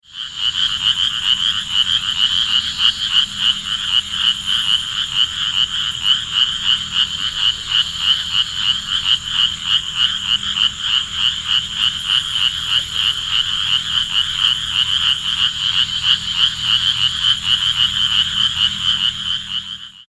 Spotted Chorus Frog - Pseudacris clarkii
Advertisement Calls
The advertisement call of the Spotted Chorus Frog is a fast series of rasping trills. A group of frogs calling at a distance can sound like sawing.
sound  This is a 20 second recording of the advertisement calls of a larger and more distant group of Spotted Chorus Frogs recorded at night in May in Travis County, Texas at the location shown below. Great Plains Narrow-mouthed Toads and insects are heard in the background.